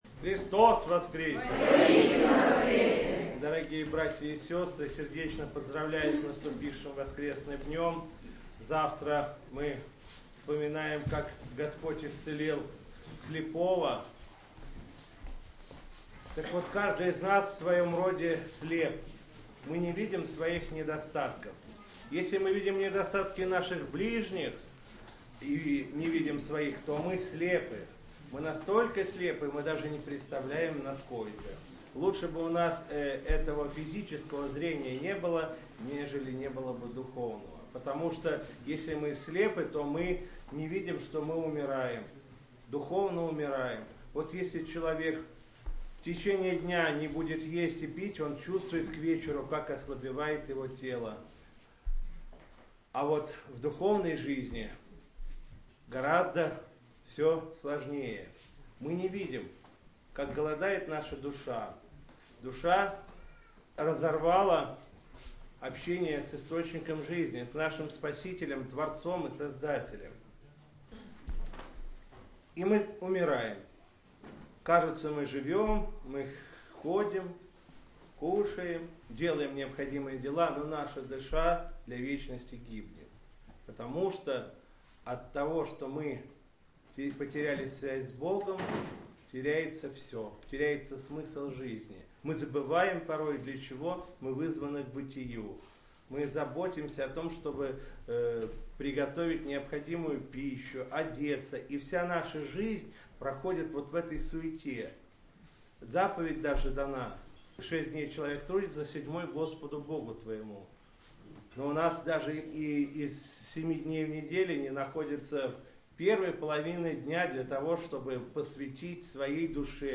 По окончании богослужения владыка Игнатий поздравил присутствующих с воскресным днем, произнес проповедь и пожелал всем помощи Божией.
Слово после всенощной в Вознесенском храме Сокола